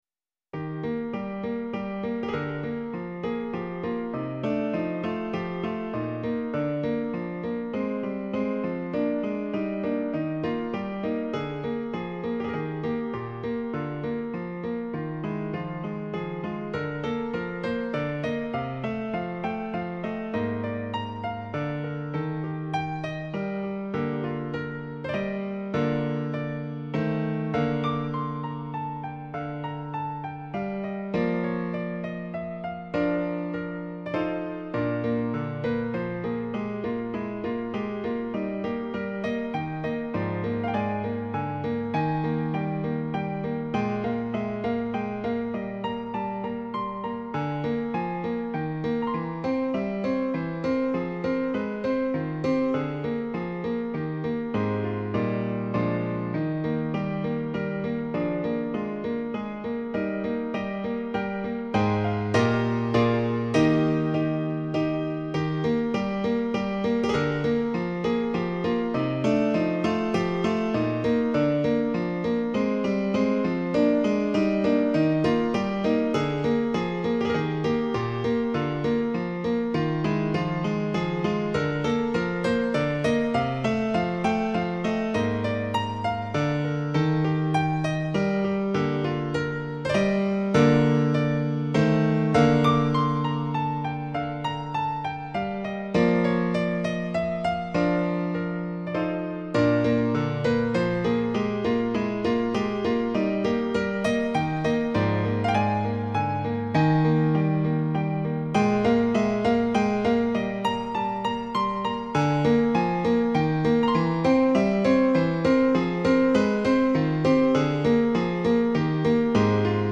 • Fragment de sonate - dictée par l'esprit de Mozart